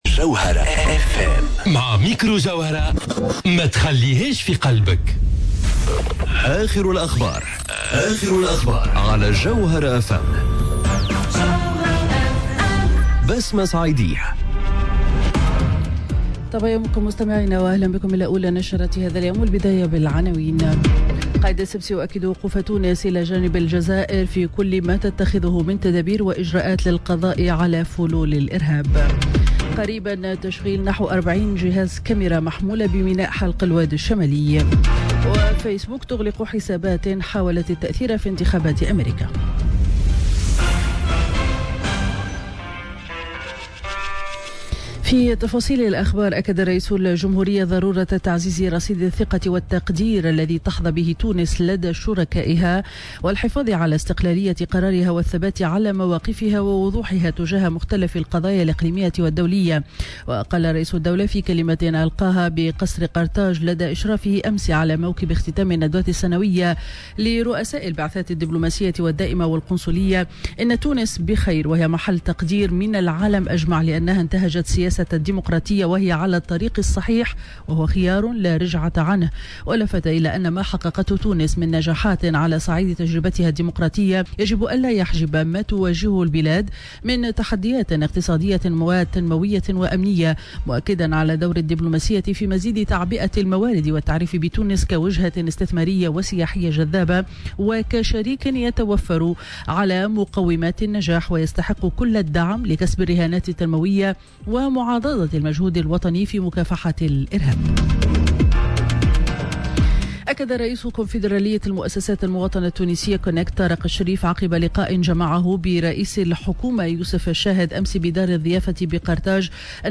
نشرة أخبار السابعة صباحا ليوم الإربعاء 01 أوت 2018